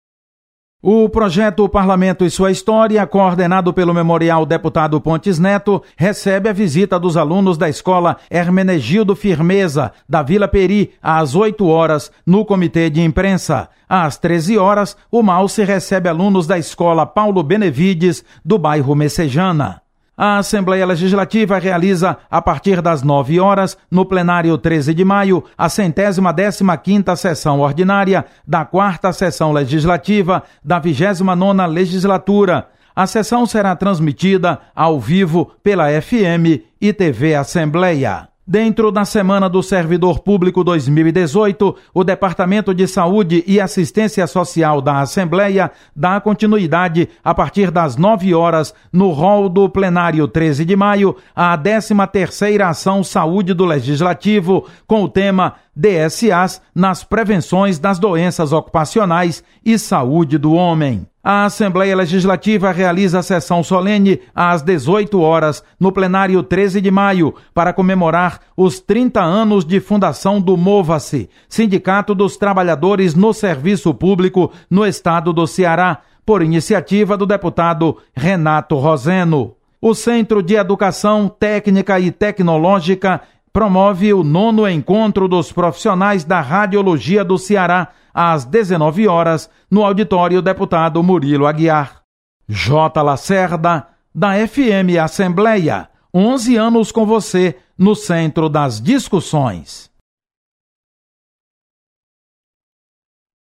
Você está aqui: Início Comunicação Rádio FM Assembleia Notícias Agenda